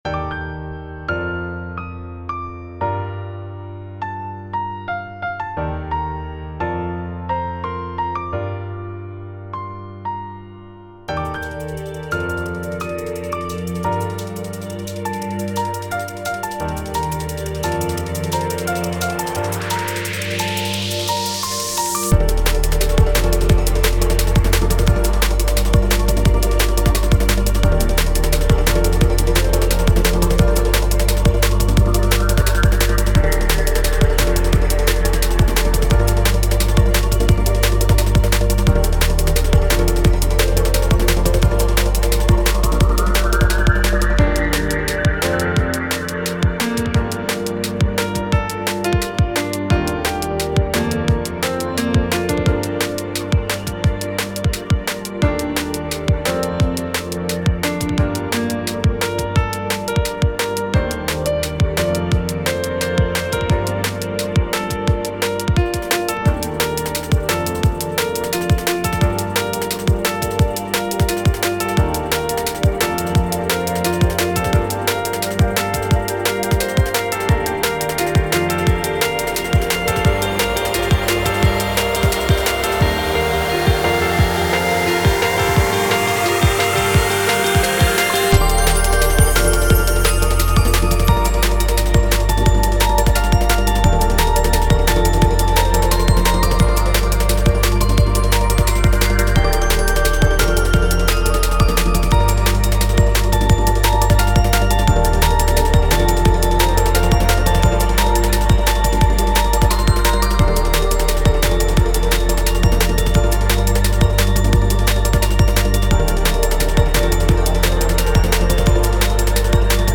タグ: DnB アンビエント 幻想的 コメント: ピアノがメインの幻想的なDrum'n'Bass楽曲。